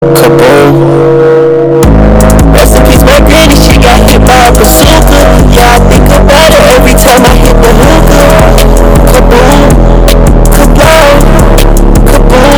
Sound Effects
Bazooka BASS BOOSTED